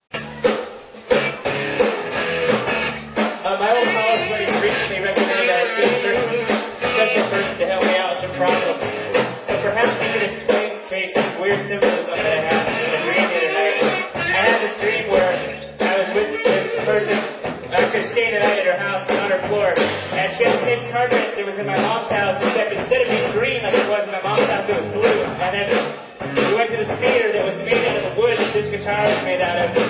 Central Tavern